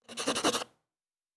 Fantasy Interface Sounds
Writing 7.wav